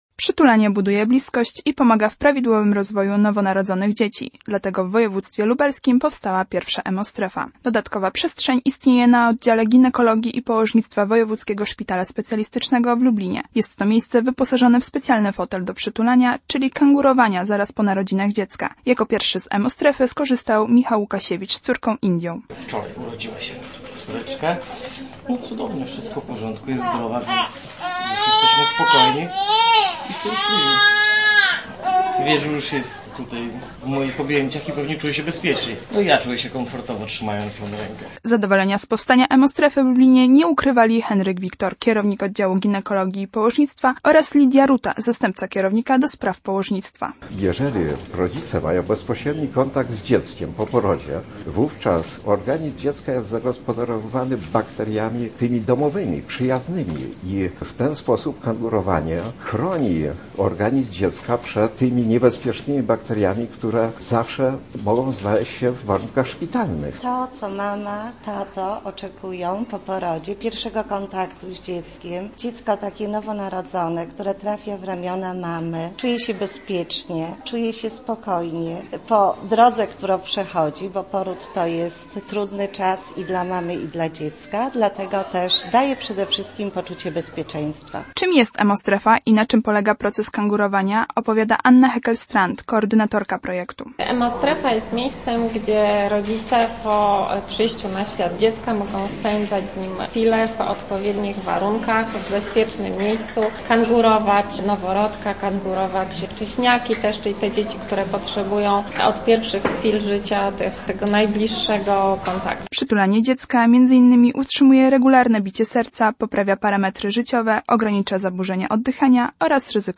Na czym polega kangurowanie i dlaczego taka strefa jest ważna dla młodych rodziców sprawdziła nasza reporterka